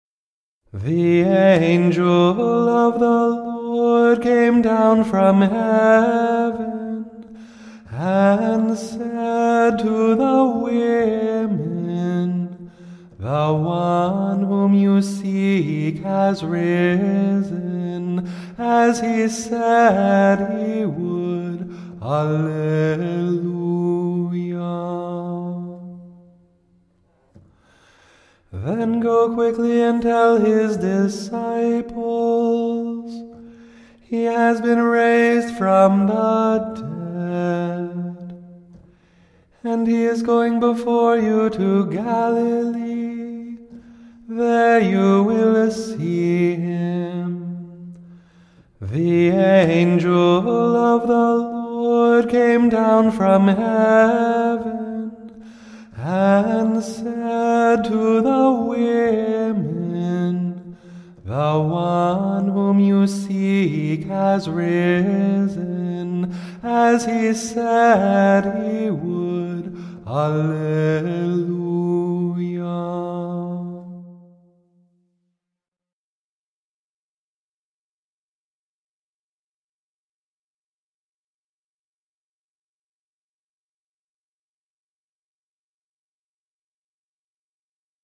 Antiphon